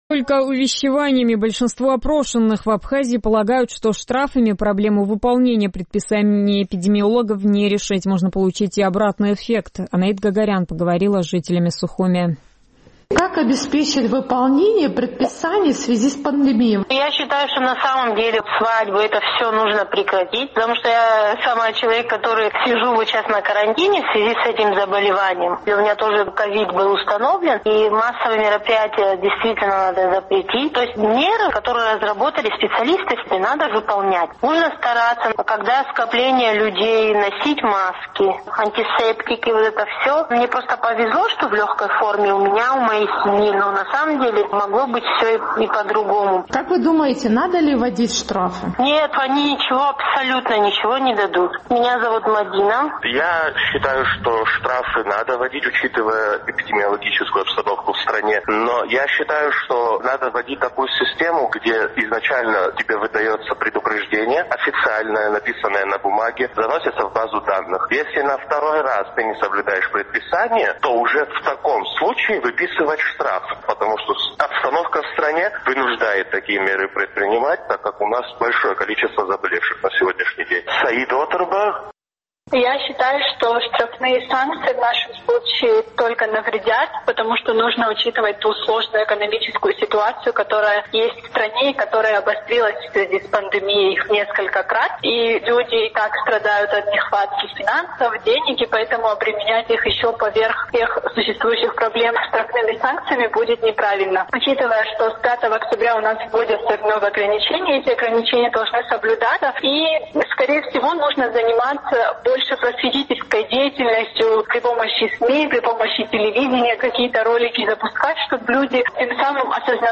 Большинство опрошенных в Абхазии полагают, что штрафами проблему выполнения предписаний эпидемиологов не решить, можно получить обратный эффект. Наш традиционный сухумский опрос.